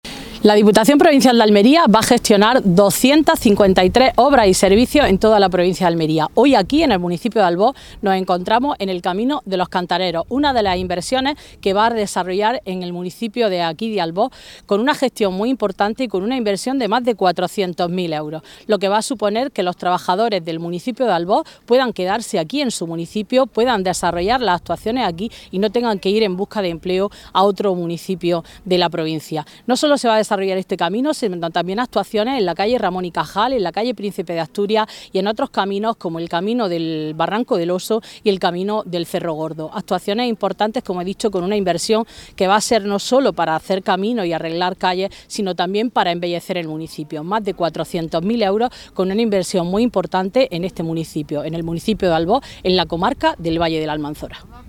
Así lo ha detallado la diputada del PFEA, Matilde Díaz, en la visita a las obras, quien ha estado acompañada de la delegada territorial de Justicia, Administración Local y Función Pública, Rebeca Gómez, y de la alcaldesa de Albox, María del Mar Alfonso.
AUDIO-PFEA-diputada.mp3